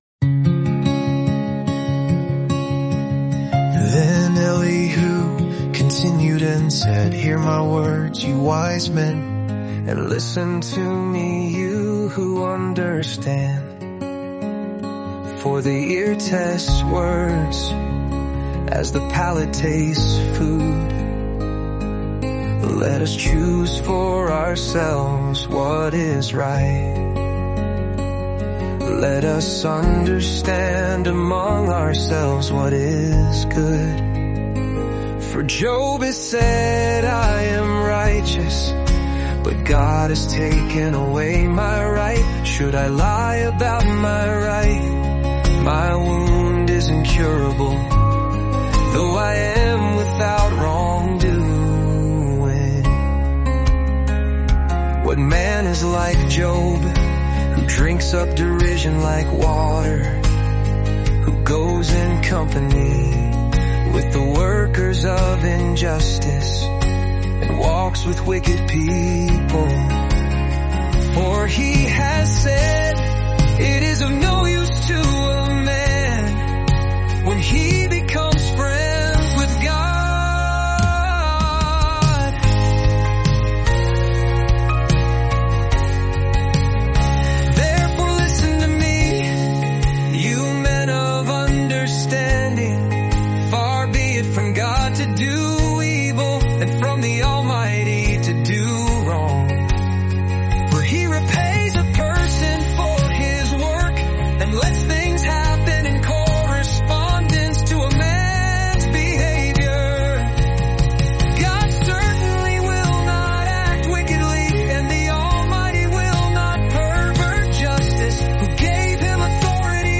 Walk with Job through his journey of deep suffering, honest questions, and steadfast faith in just 14 days through word-for-word Scripture songs.